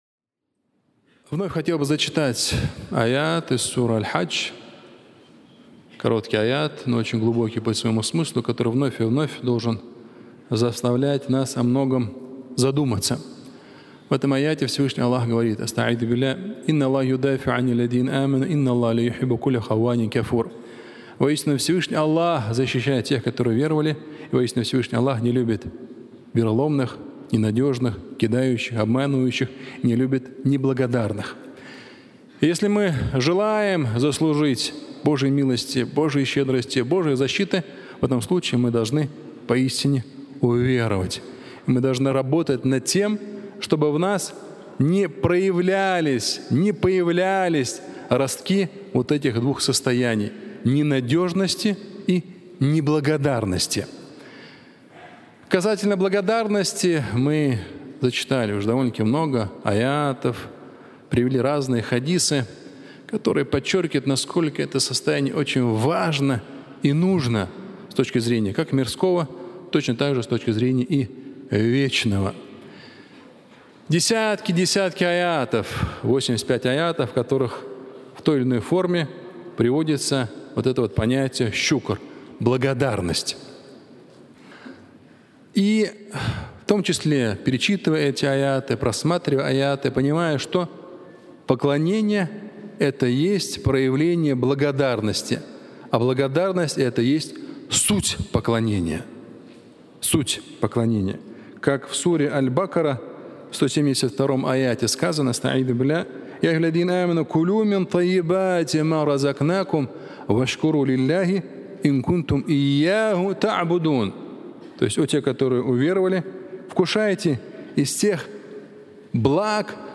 Суть поклонения (аудиолекция)